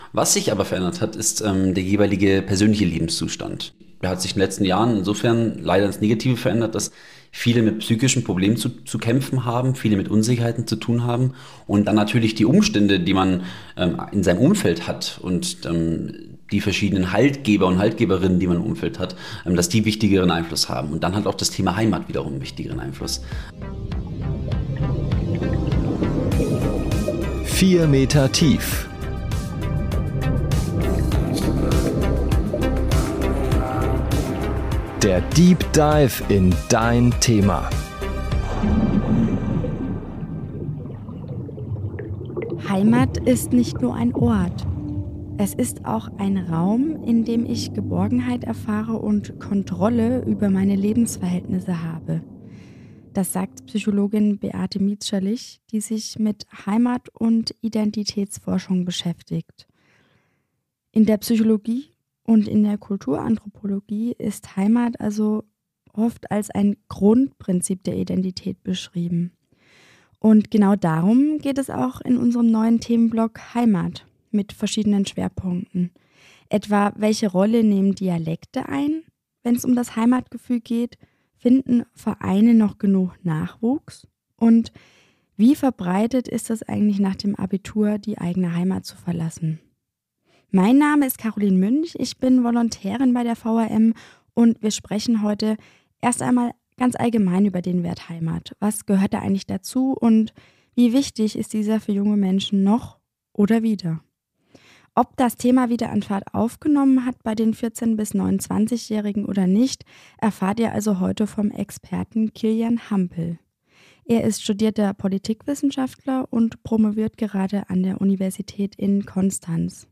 Zwei Jugendliche aus Mainz erzählen zum Schluss, was sie persönlich mit Heimat verbinden.